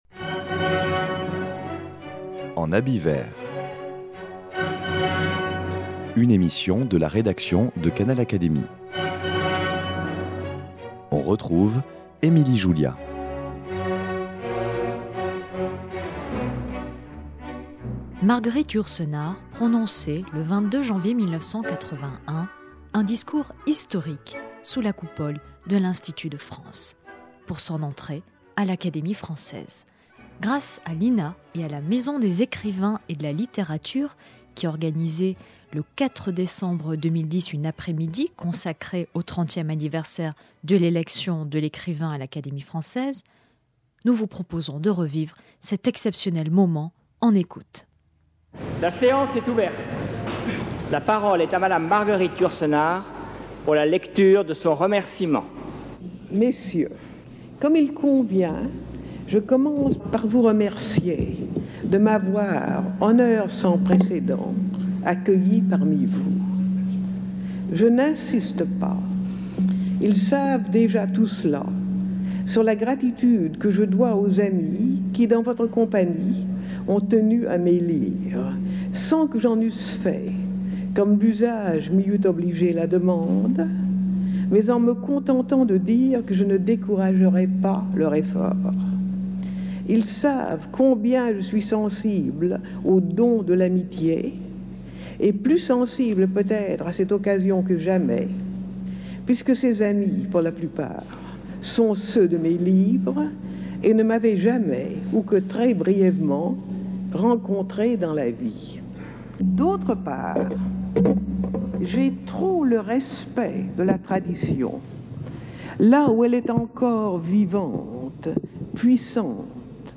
Marguerite Yourcenar prononçait le 22 janvier 1981 un discours historique sous la Coupole de l’Institut de France pour son entrée à l’Académie française.
Vous pouvez ici entendre la voix assurée et si particulière de Marguerite Yourcenar à l'occasion de son discours de réception prononcé à l'Académie française.